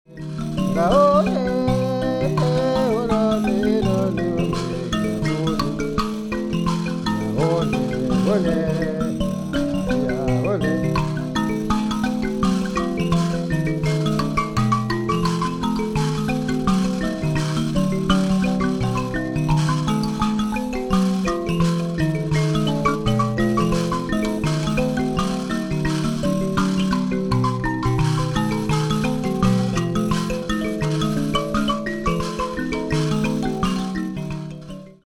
Both master mbira players and master mbira makers